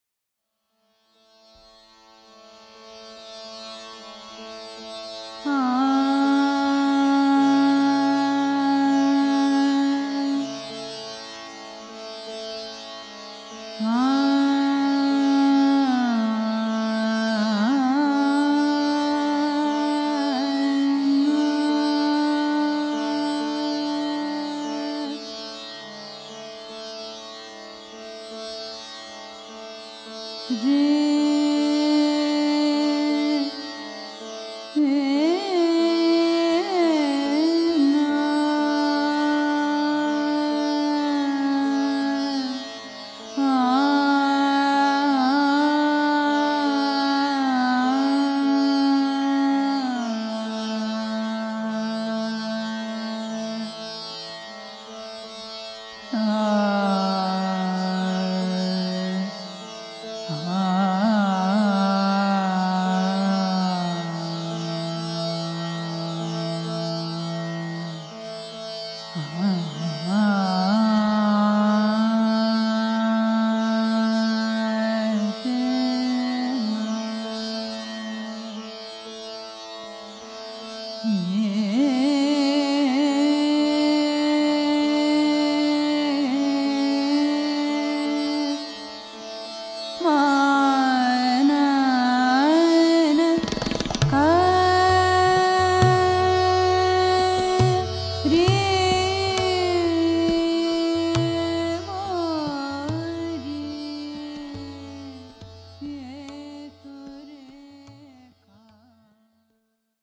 Classical Raag's